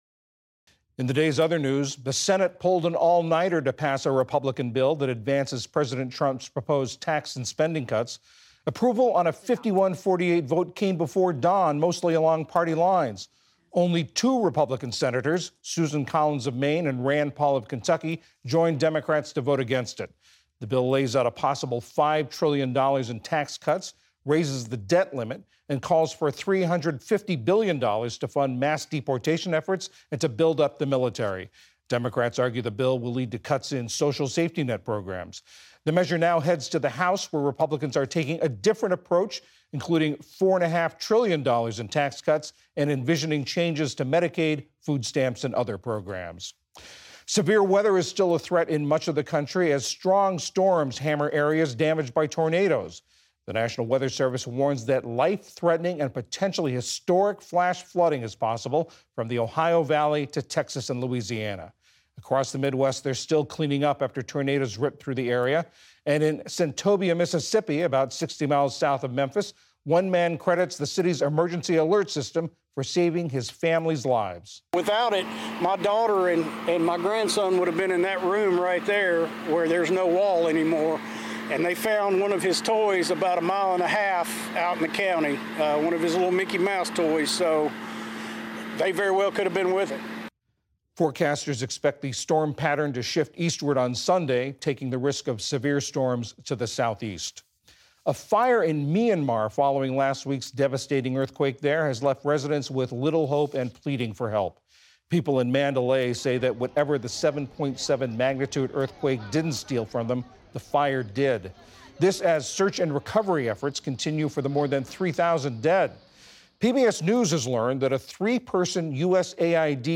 News, Daily News